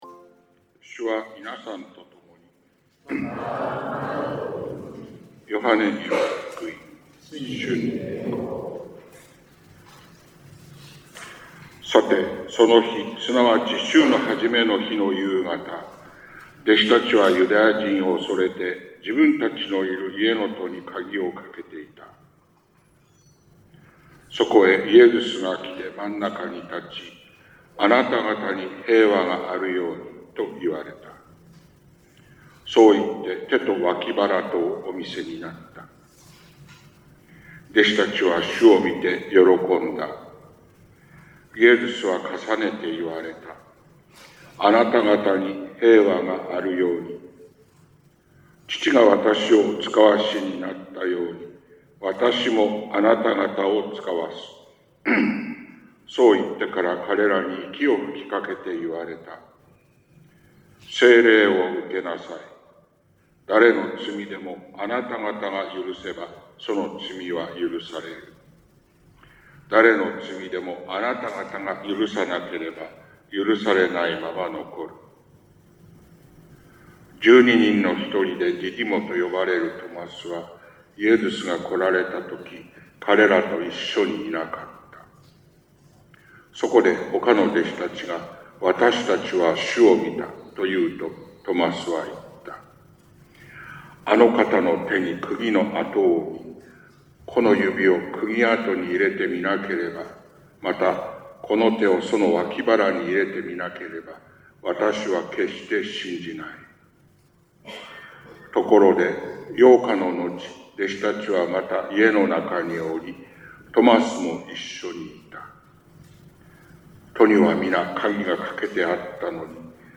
福音朗読（音声）